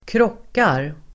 Uttal: [²kr'åk:ar]